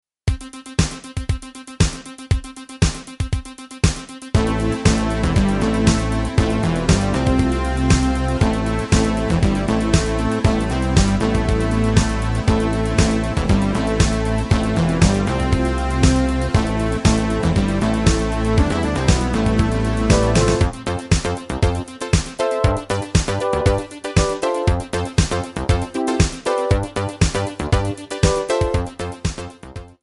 MPEG 1 Layer 3 (Stereo)
Backing track Karaoke
Pop, Duets, 1980s